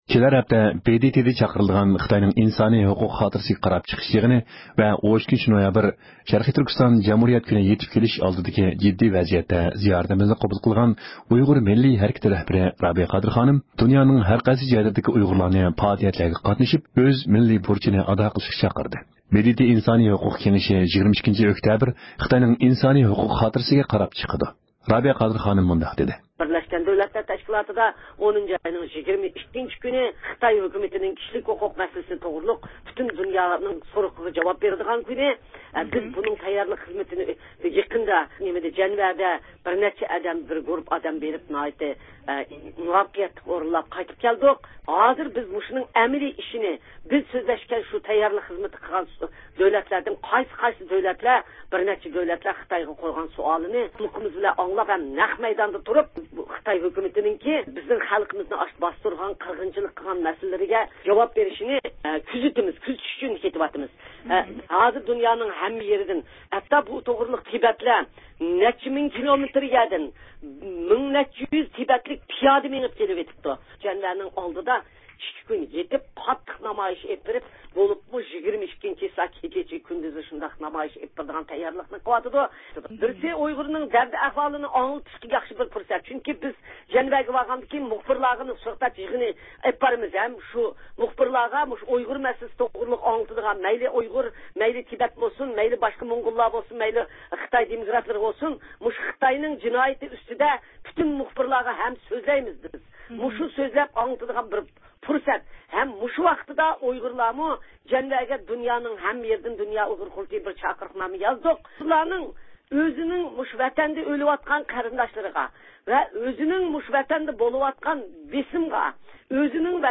erkin-asiya-radiosi.jpgئەركىن ئاسىيا رادىئوسى ئۇيغۇر بۆلۈمى ھەپتىلىك خەۋەرلىرى